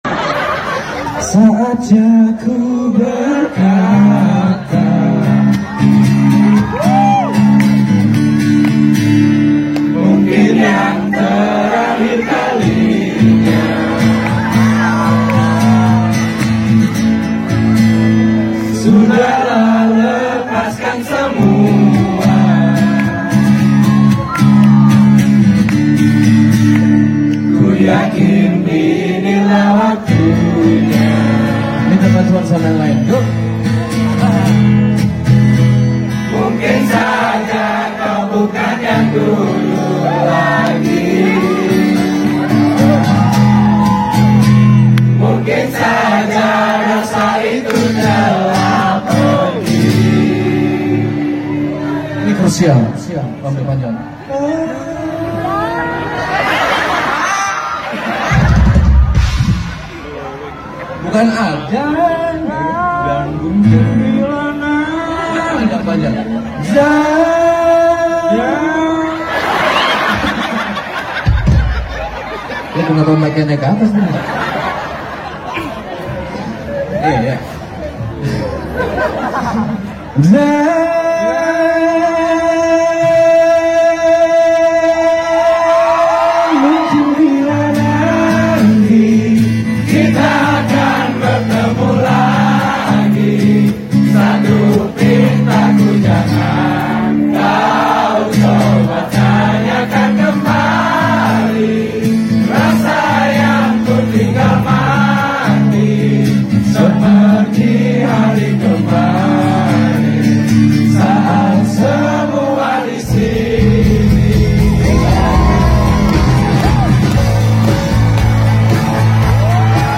Hard Rock Bali